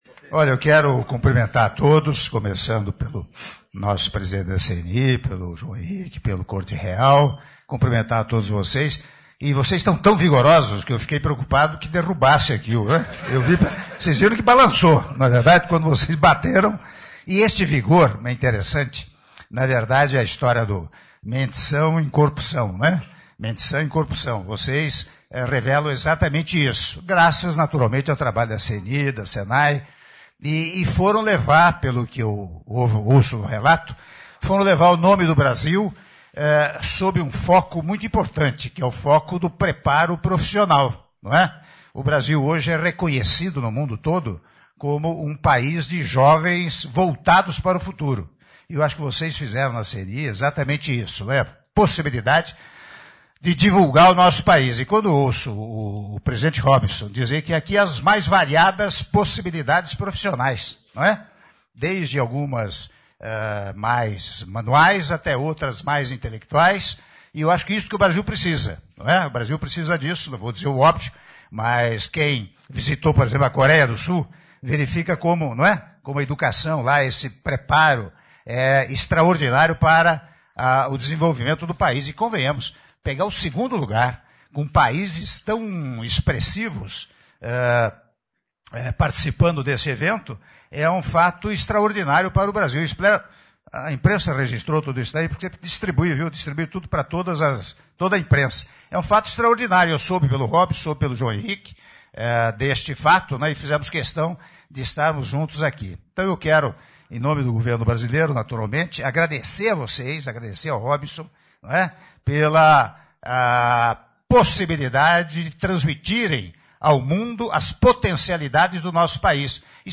Áudio do discurso do Presidente da República, Michel Temer, durante encontro com a Delegação de Estudantes do Senai, medalhistas do 44º Edição do WorldSkills - Brasília/DF- (03min17s)